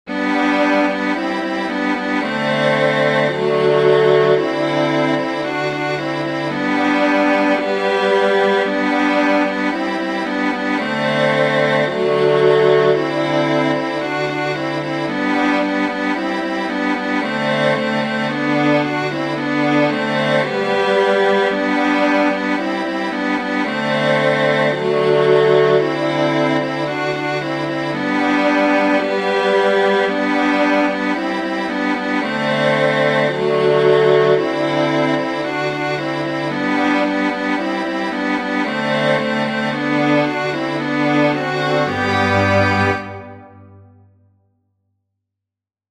Musiche digitali in mp3 tratte dagli spartiti dell'opuscolo